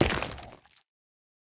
Minecraft Version Minecraft Version 25w18a Latest Release | Latest Snapshot 25w18a / assets / minecraft / sounds / block / shroomlight / step2.ogg Compare With Compare With Latest Release | Latest Snapshot